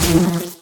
Fly_bug_hit2.ogg